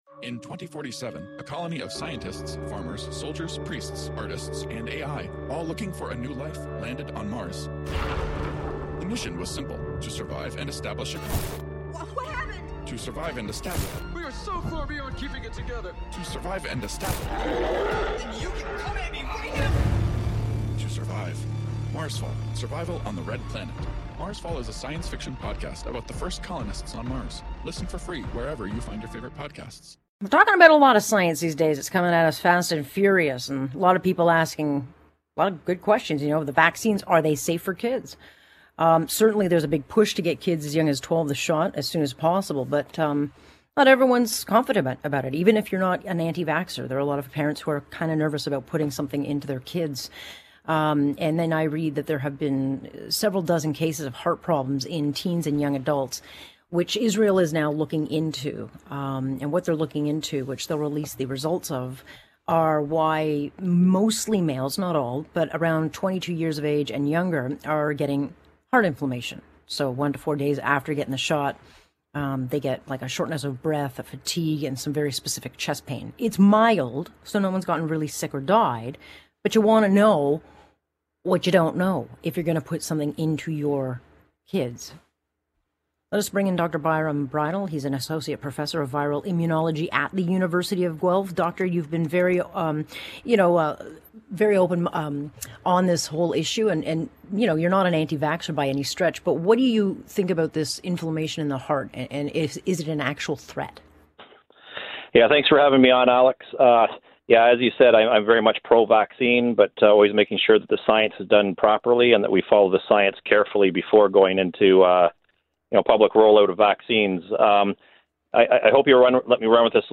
Was sent this ~9 minute interview earlier today: